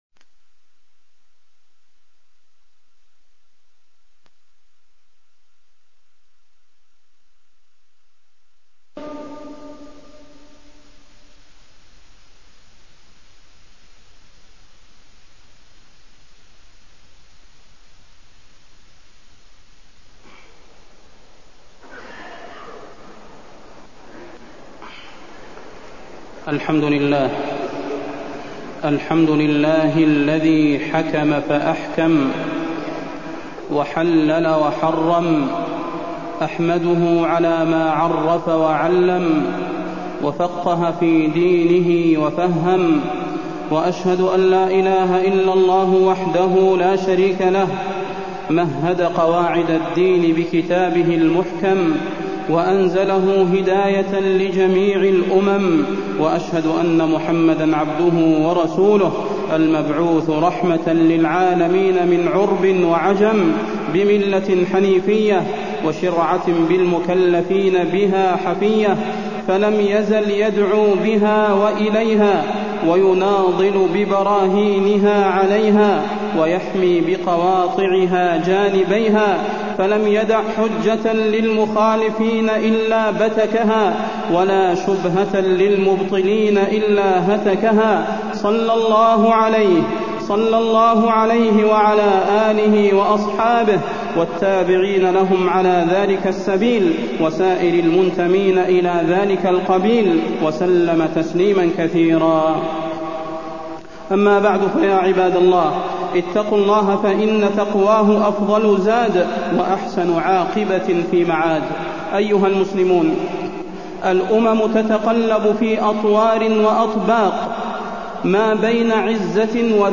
تاريخ النشر ٦ صفر ١٤٢٣ المكان: المسجد النبوي الشيخ: فضيلة الشيخ د. صلاح بن محمد البدير فضيلة الشيخ د. صلاح بن محمد البدير طريق العزة والتمكين للأمة